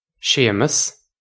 Séamas Shay-muss
This is an approximate phonetic pronunciation of the phrase.